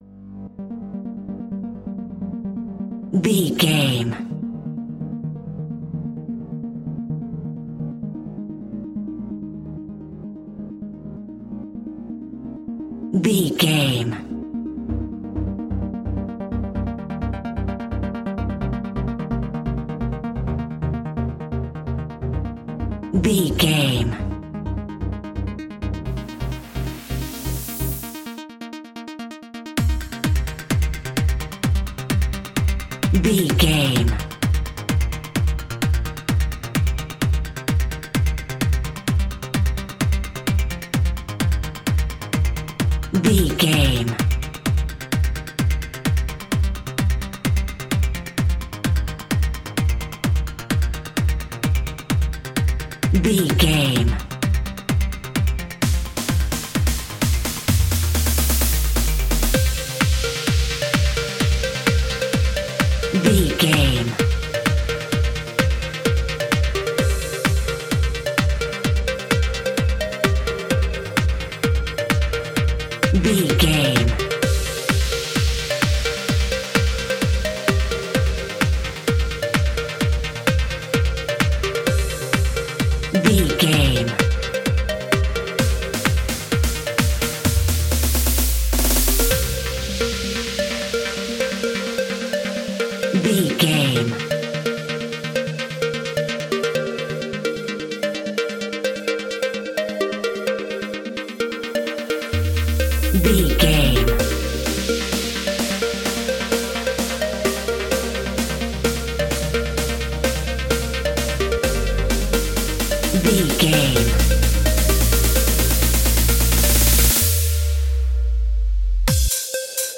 Epic / Action
Fast paced
Aeolian/Minor
aggressive
dark
intense
energetic
driving
synthesiser
drums
drum machine
futuristic
breakbeat
synth leads
synth bass